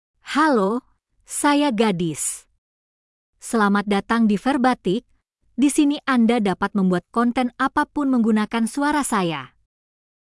GadisFemale Indonesian AI voice
Gadis is a female AI voice for Indonesian (Indonesia).
Voice sample
Listen to Gadis's female Indonesian voice.
Female
Gadis delivers clear pronunciation with authentic Indonesia Indonesian intonation, making your content sound professionally produced.